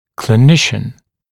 [klɪ’nɪʃn][кли’нишн]клиницист, клинический врач
clinician.mp3